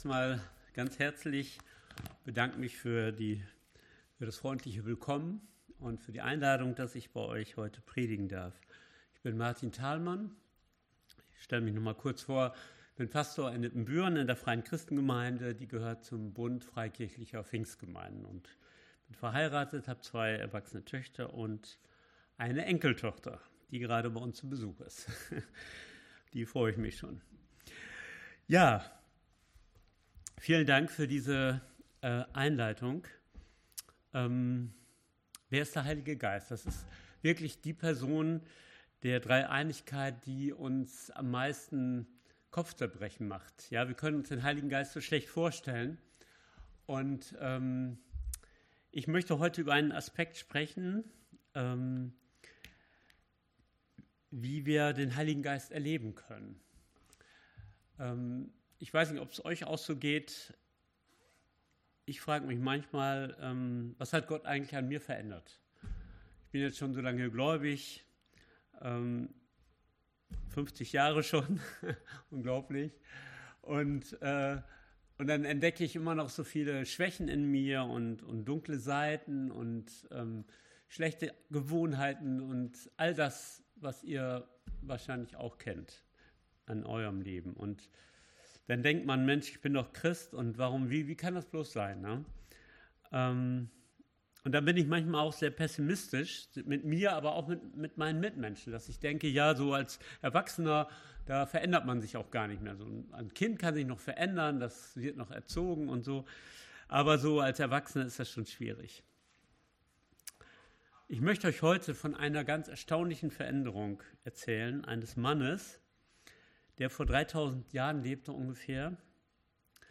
Evangelisch-Freikirchliche Gemeinde Borken - Predigten anhören